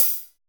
FUNKY 1 2 P.wav